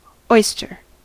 Ääntäminen
US : IPA : [ˈɔɪs.tɜː] UK : IPA : /ˈɔɪ.stə(ɹ)/